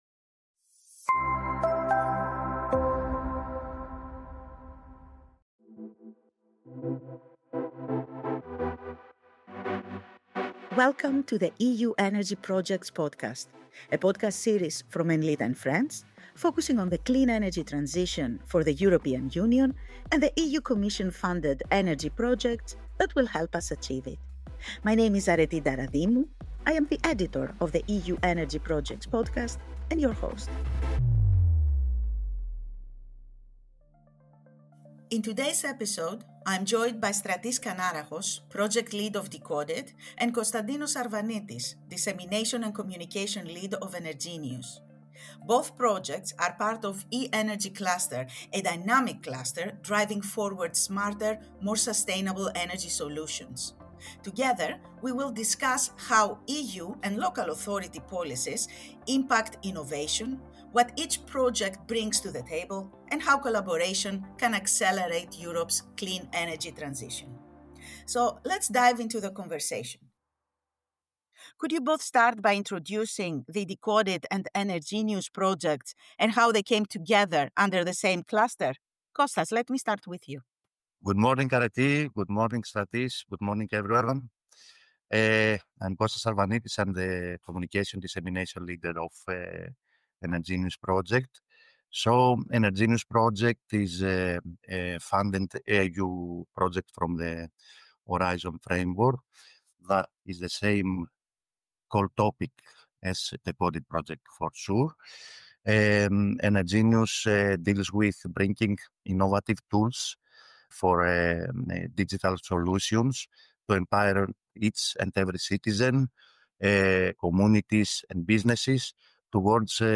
The EU Energy Projects Podcast is an insider’s look into the world of EU-funded projects transforming the energy sector in Europe. Every fortnight we focus on a new topic in the energy transition and talk to representatives from projects that are enabling the transition to green energy by finding solutions to the sector’s most pressing problems.